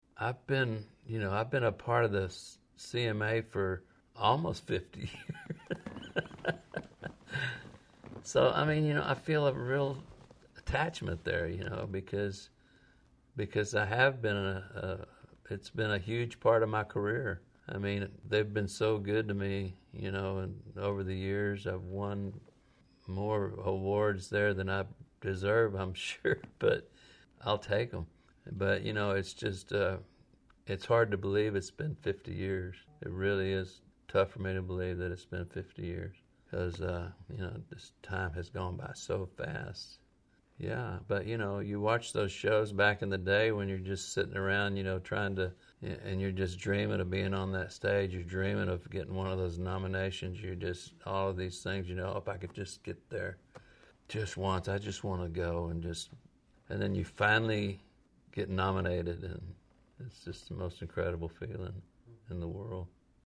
Audio / George Strait talks about realizing his dream of winning CMA Awards.